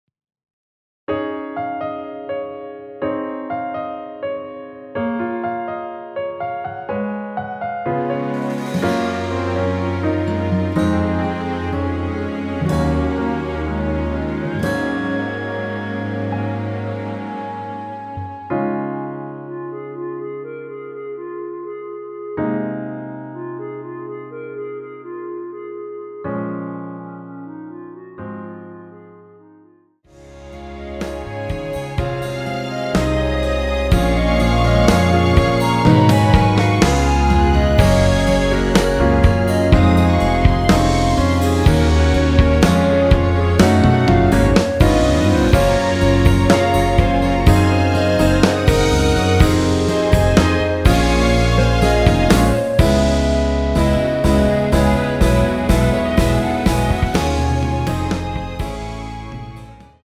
원키 멜로디 포함된 1절후 후렴으로 진행 되는 MR입니다.(본문 가사 확인)
Db
앞부분30초, 뒷부분30초씩 편집해서 올려 드리고 있습니다.
중간에 음이 끈어지고 다시 나오는 이유는